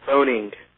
Ääntäminen
Ääntäminen US : IPA : [ˈfoʊn.ɪŋ] Tuntematon aksentti: IPA : /ˈfəʊn.ɪŋ/ Haettu sana löytyi näillä lähdekielillä: englanti Käännöksiä ei löytynyt valitulle kohdekielelle.